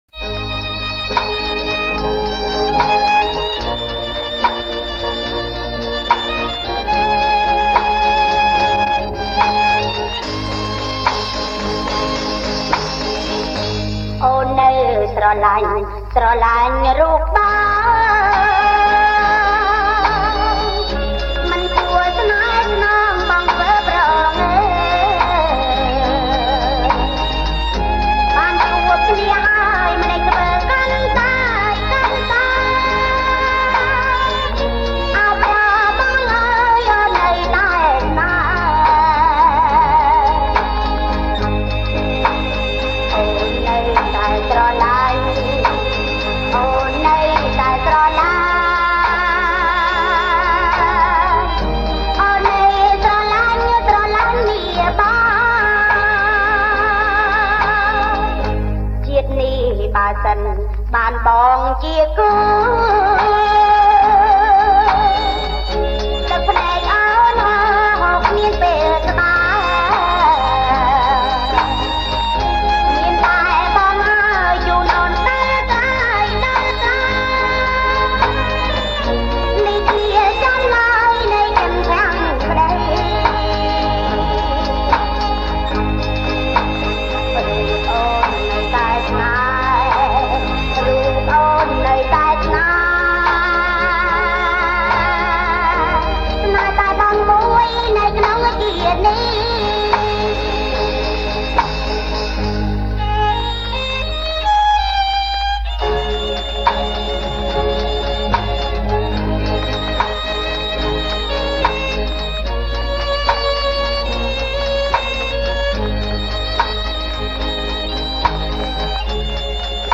• ប្រគំជាចង្វាក់ Blue